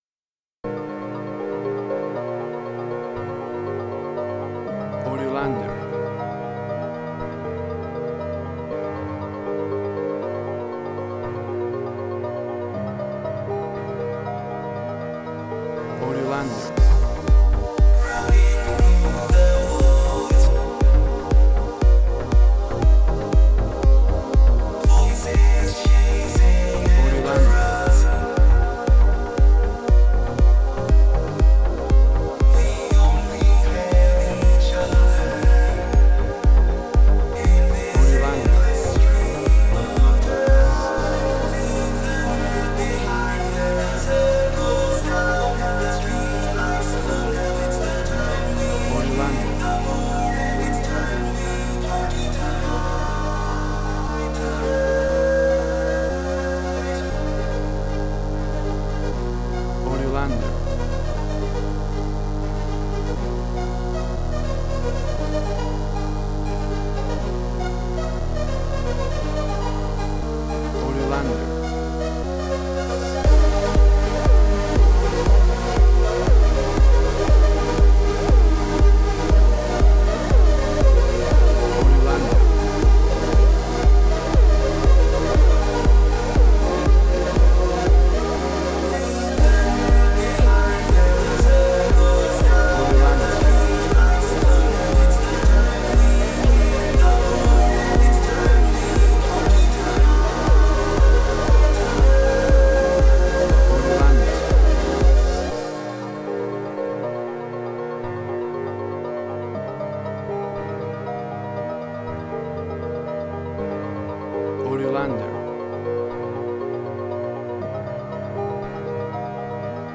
WAV Sample Rate: 16-Bit stereo, 44.1 kHz
Tempo (BPM): 120